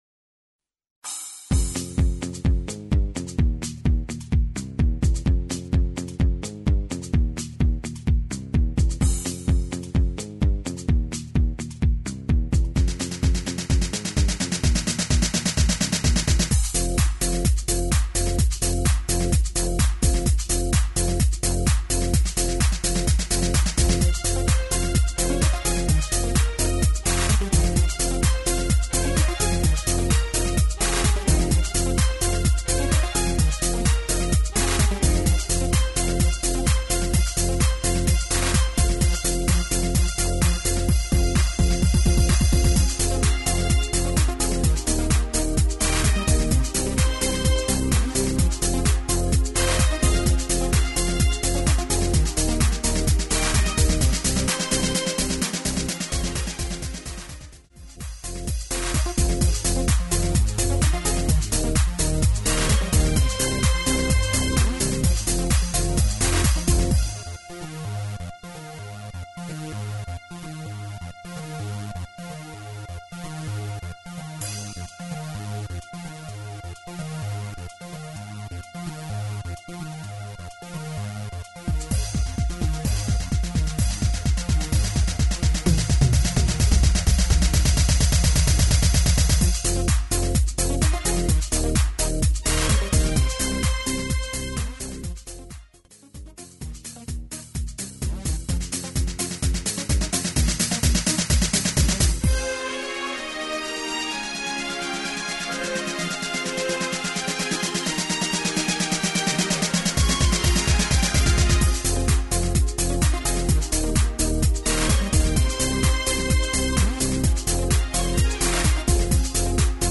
HD = Hoedown/Patter